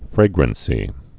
(frāgrən-sē)